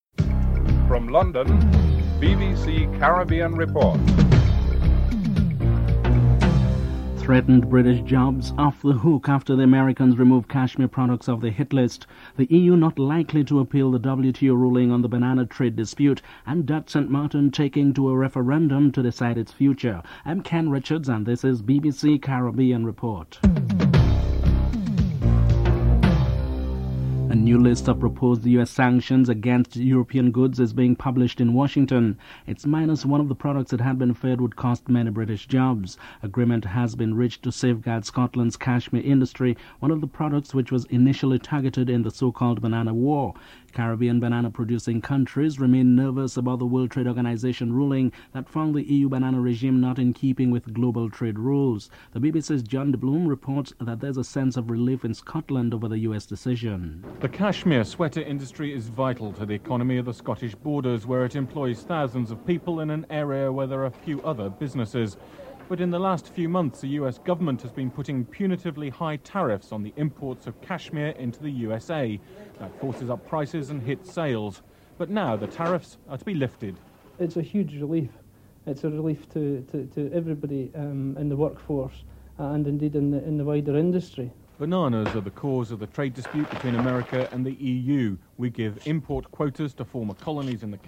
1. Headlines